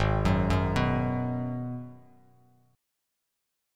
G#m7 Chord